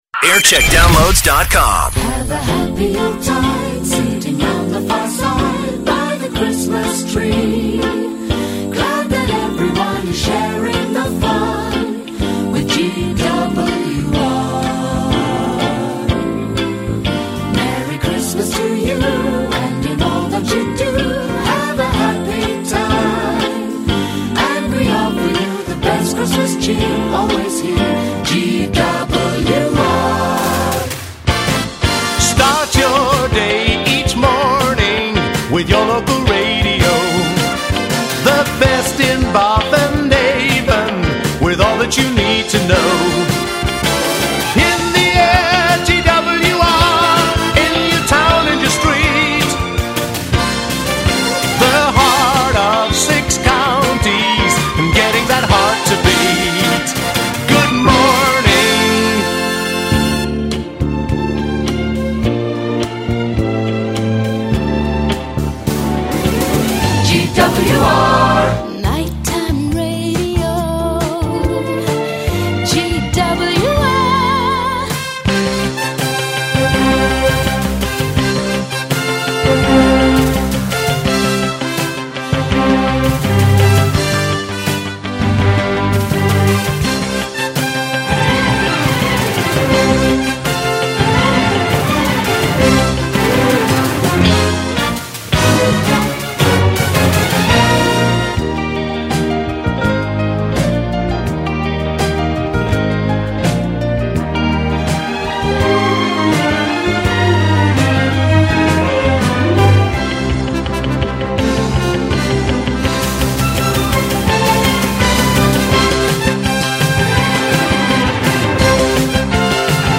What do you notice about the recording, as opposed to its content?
....local radio sounded like this.